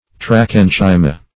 Search Result for " trachenchyma" : The Collaborative International Dictionary of English v.0.48: Trachenchyma \Tra*chen"chy*ma\, n. [NL.,fr. trachea + -enchyma as in E. parenchyma.]